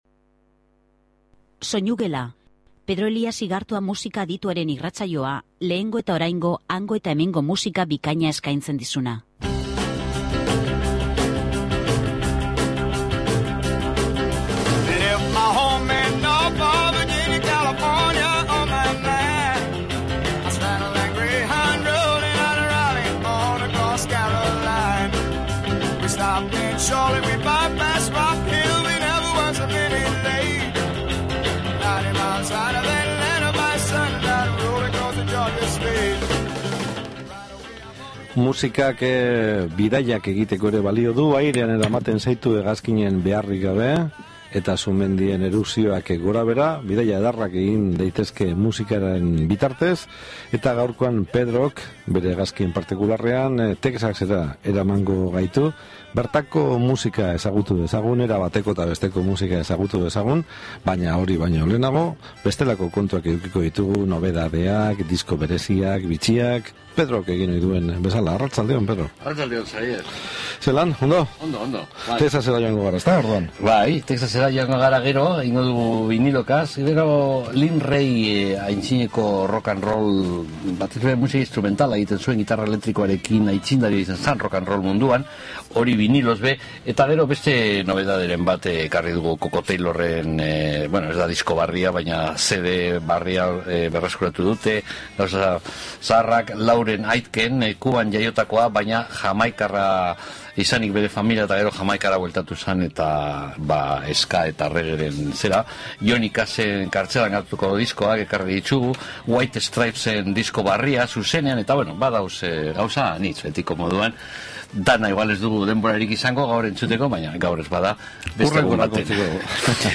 jazz musikaren emakumezko kantarien
soul
gitarrajolearen